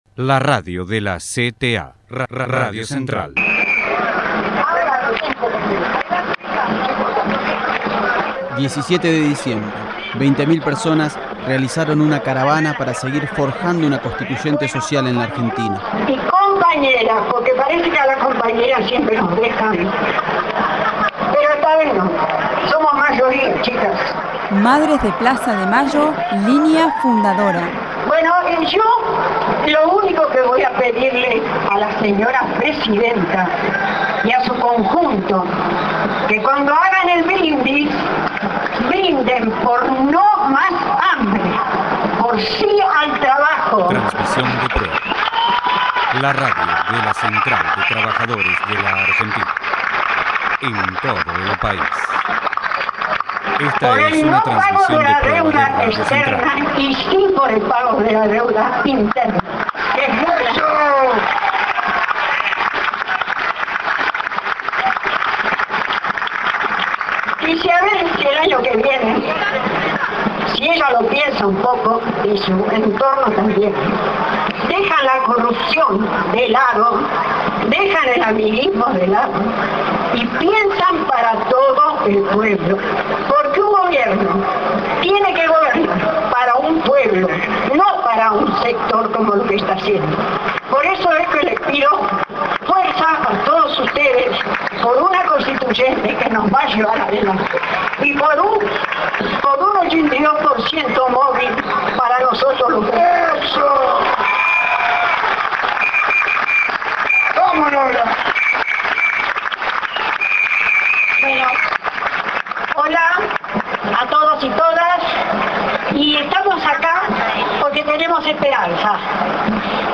Masivo acto frente al Congreso Nacional
17-12_MADRES_MARCHA.mp3